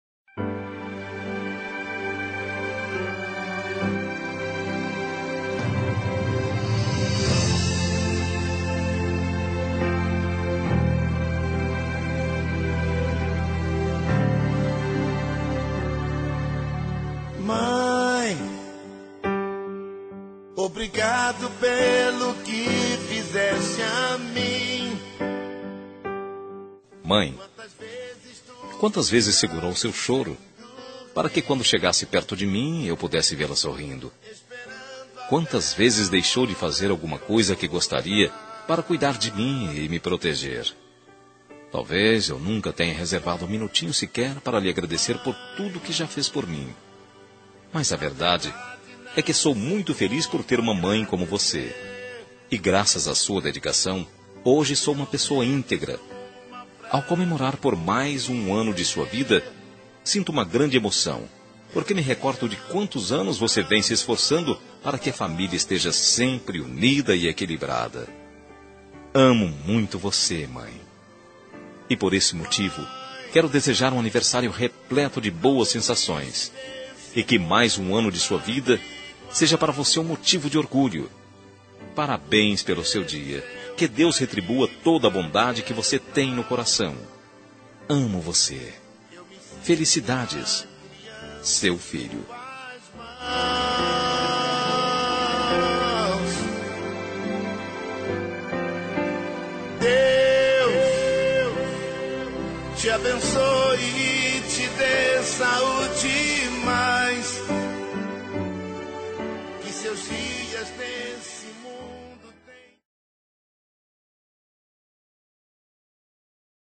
Telemensagem de Aniversário de Mãe – Voz Masculina – Cód: 1445